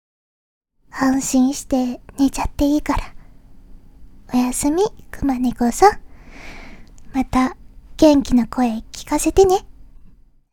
やさしい声は、いちばん効くおくすり💊
ボイスサンプル